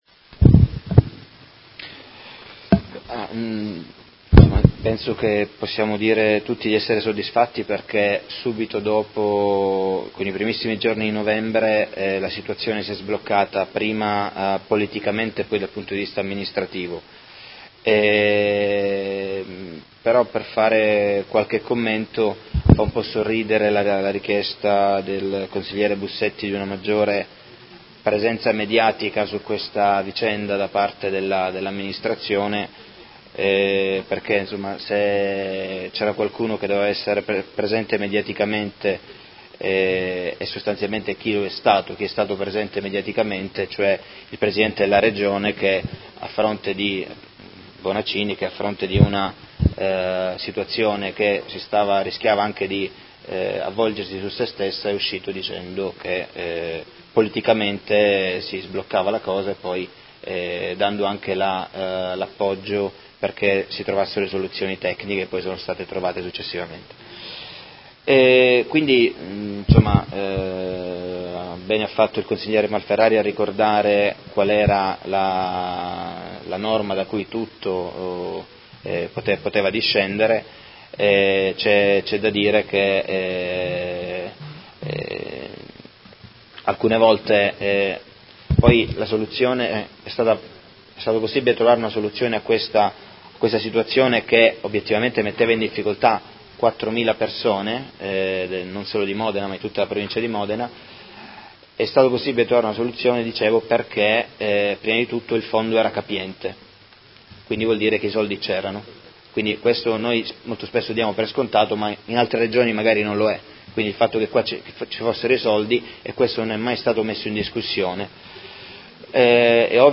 Seduta del 10/01/2019 Replica a risposta Assessora Urbelli. Interrogazione del Consigliere Fasano (PD) avente per oggetto: Situazione contrattuale dei lavoratori dell’AUSL di Modena